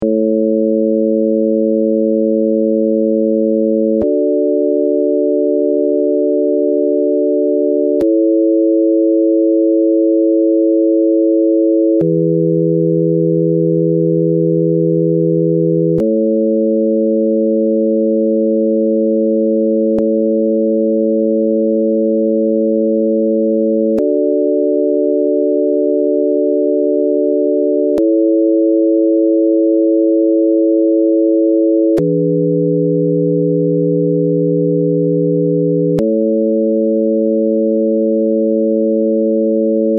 純正律・平均律カデンツァの順に
４声の合成は、電子的に２声+２声とし音響的に４声に合成する方法とする。
jt_et_cadenza.mp3